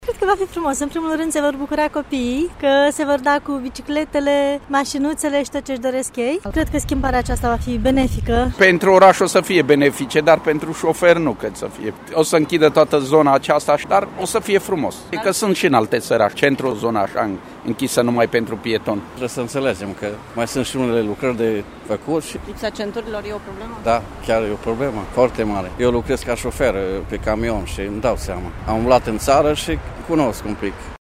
Târgumureșenii care frecventează des centrul orașului sunt încântați de inițiativă, deși admit că vor fi probleme serioase de trafic: